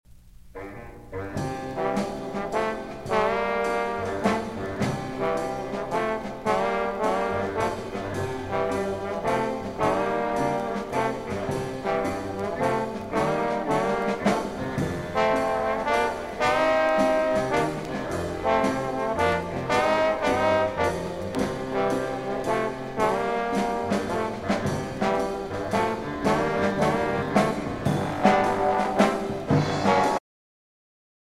(trumpet)
(bass trombone)
(alto saxophone)
(tenor saxophone)
(baritone saxophone)
(piano)
(percussion)
(vocals)
Jazz -- 1961-1970; Jazz vocals;